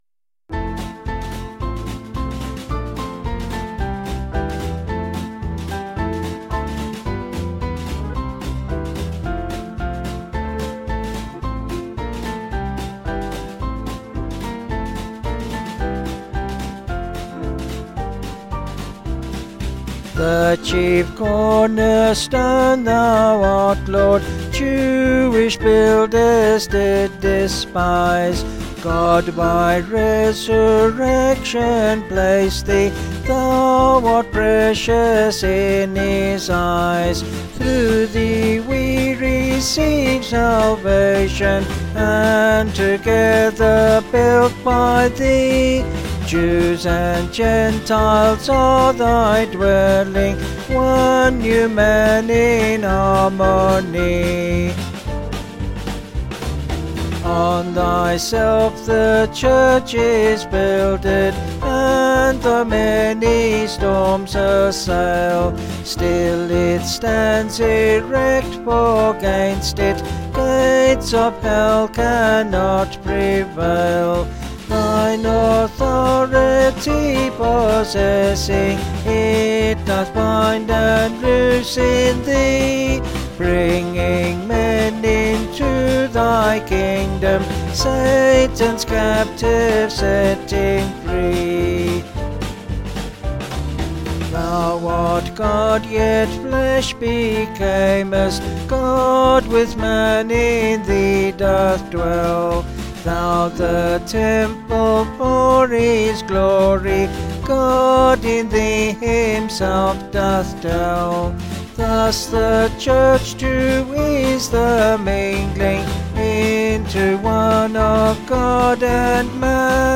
(BH)   4/F-Gb
Vocals and Band   263kb